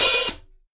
Cardi Open Hat 1.wav